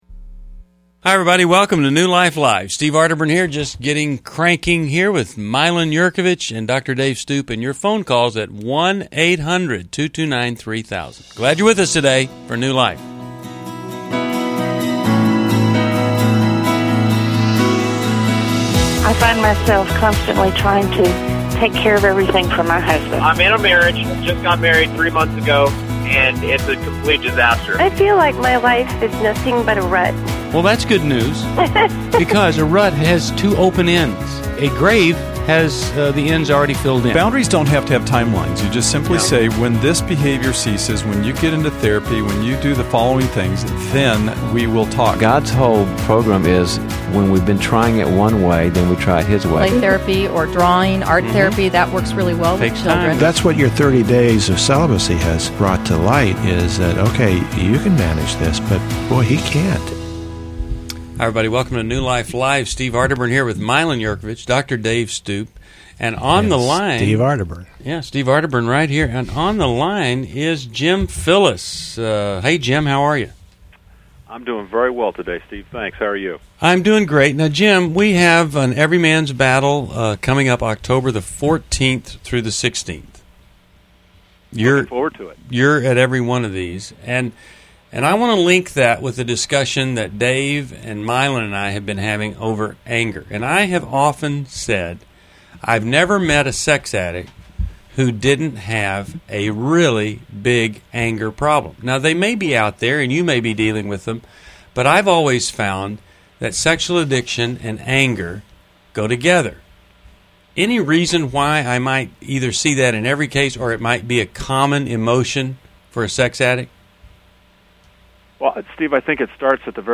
Discover insights on anger, trust, and forgiveness in relationships as the hosts tackle caller concerns on New Life Live: September 29, 2011.